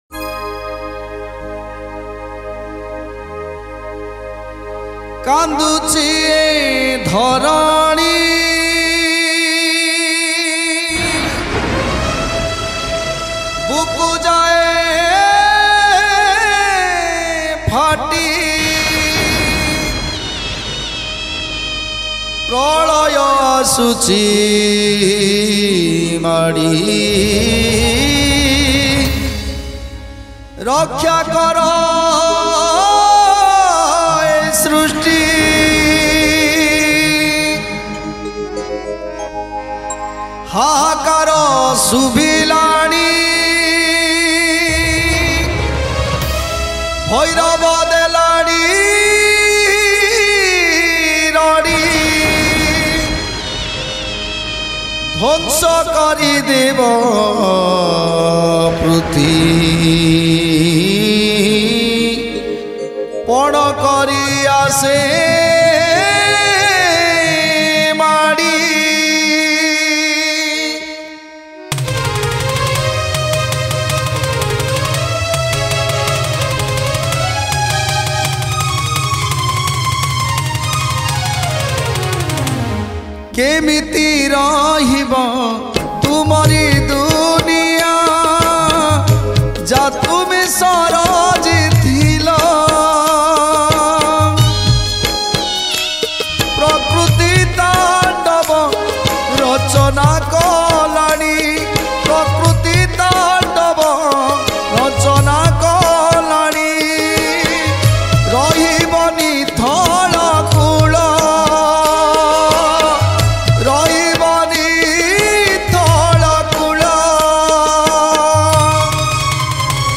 Ratha Yatra Odia Bhajan 2023 Songs Download